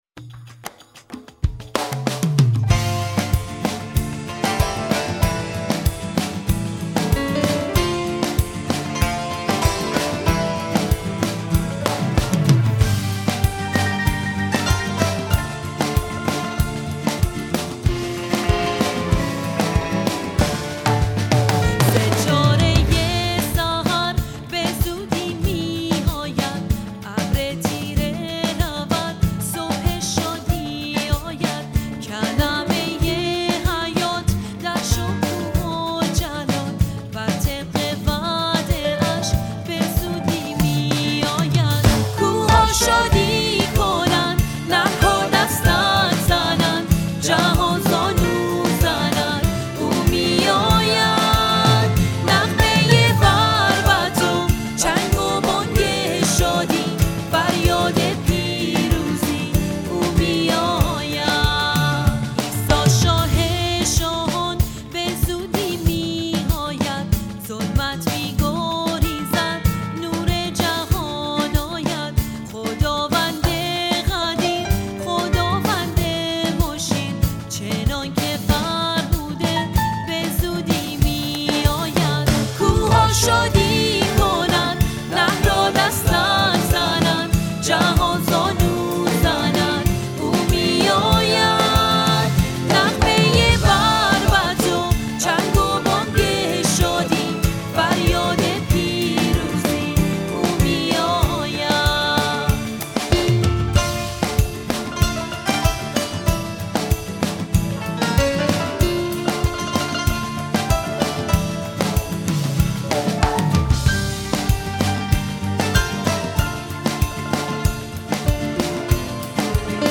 سرود پرستشی او می آید